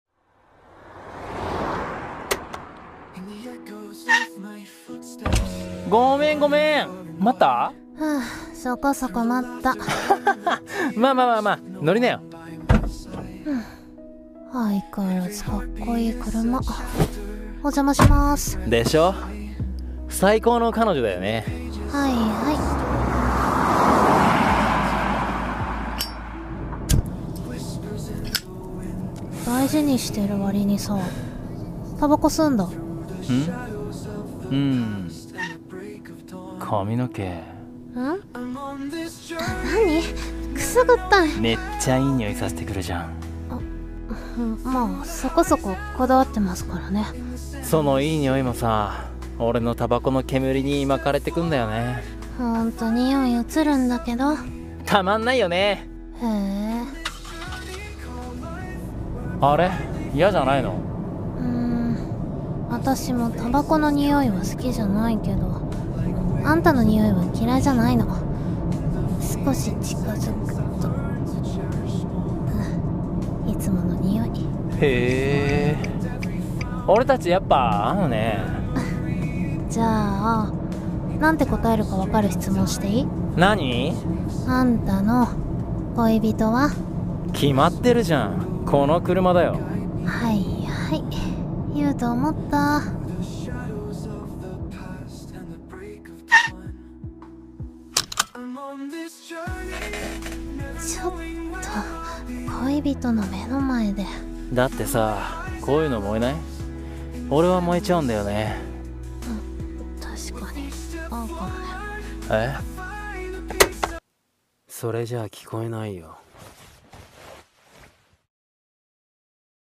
【声劇】偏食フレーバー